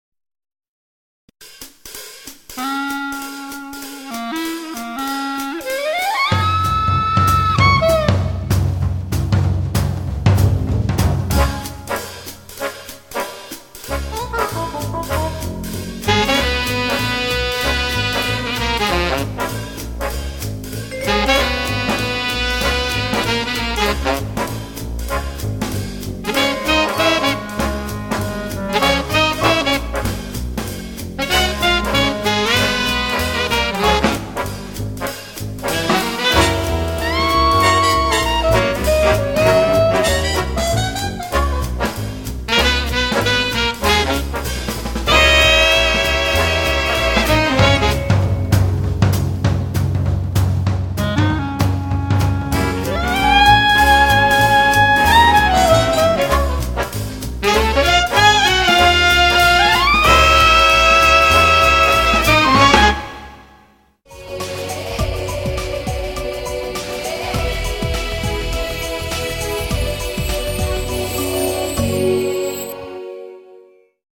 DRUMMER